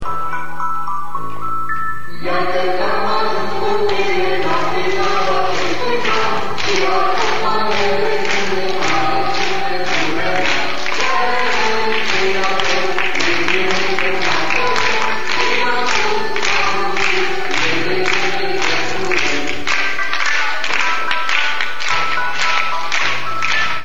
O ora de poveste, cu spectacol de teatru si muzica pe scena casei de cultura din Amara
Elevii din clasele I-IV din orasul-statiune au fost primii beneficiari ai reprezentatiei de teatru de la Chisinau. Cum piesele sunt adaptari ale povestilor lui Ion Creanga, iar personajele  vorbesc natural limba dulce moldoveneasca, actorii i-au castigat usor pe copiii spectatori: